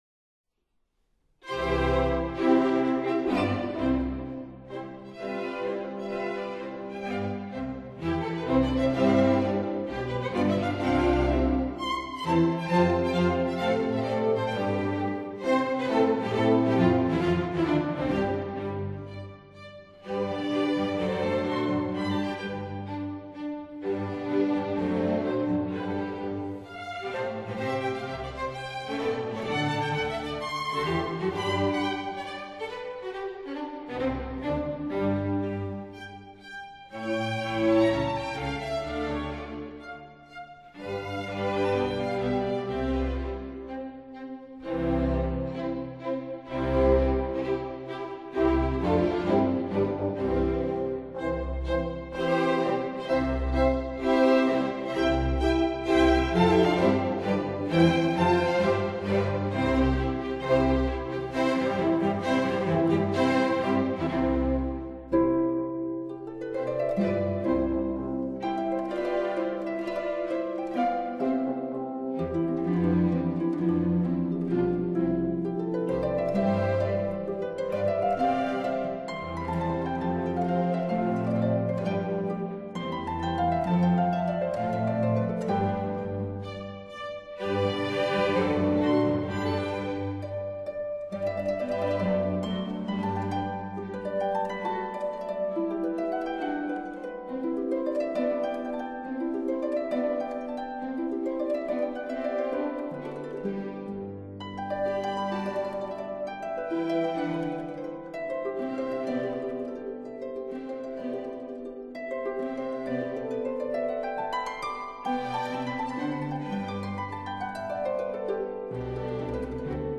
Harp Concerto in C major - I. Allegro moderato